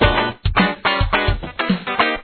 Guitar 2